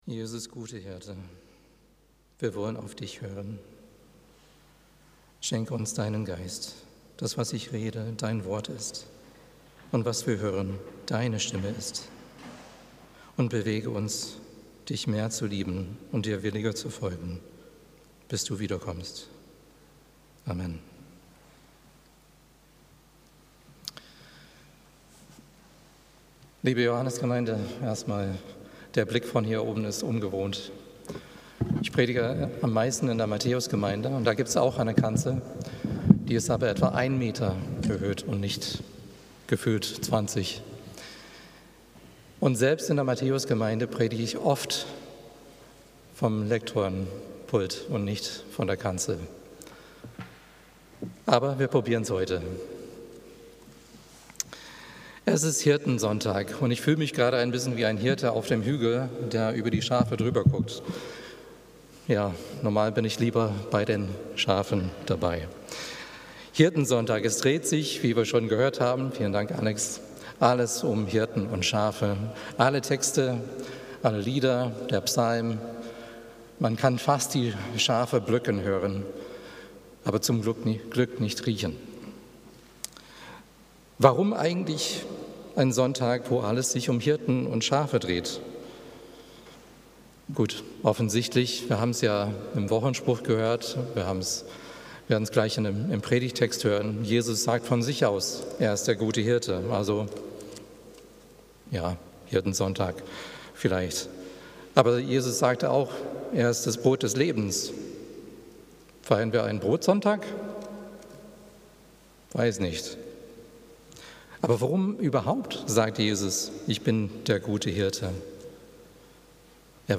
Predigten 2025